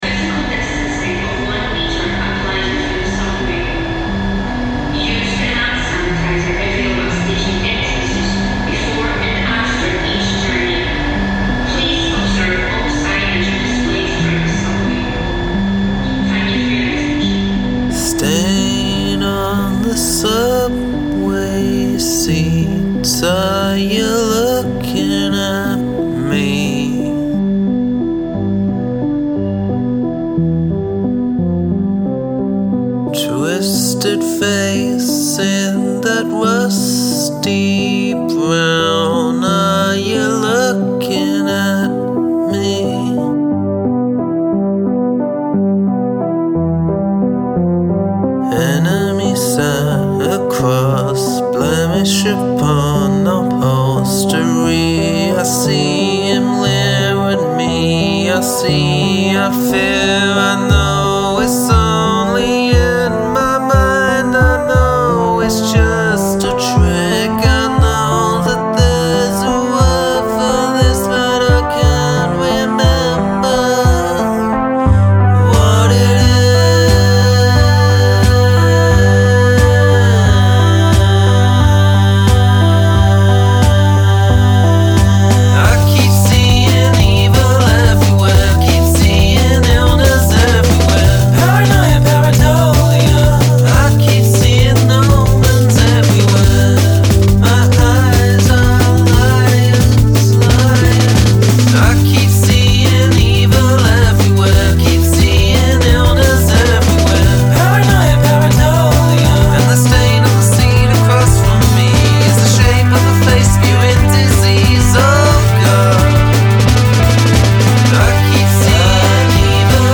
Use of field recording
Your synth work is great.